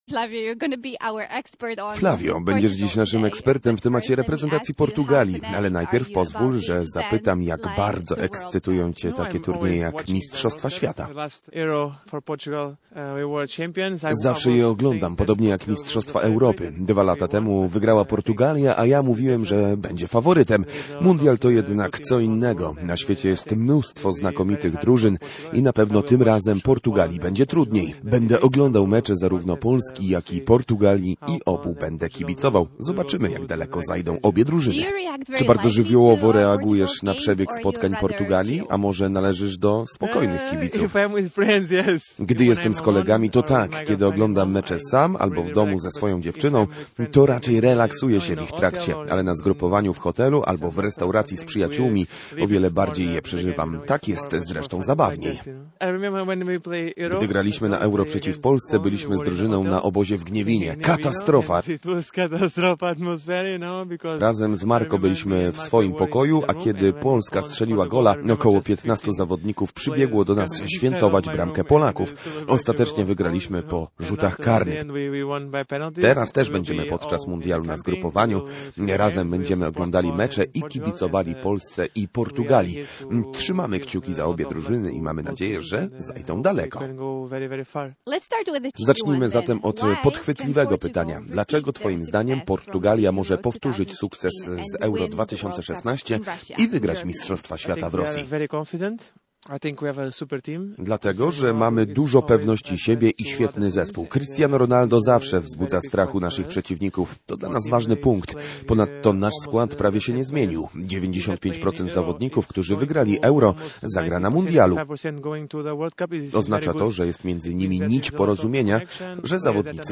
Posłuchaj rozmowy: /audio/dok2/flavio.mp3 WESOŁE ZGRUPOWANIE Już 25 czerwca Lechia ruszy na pierwszy z dwóch obozów, na których będzie kontynuowała przygotowania do nowego sezonu Ekstraklasy.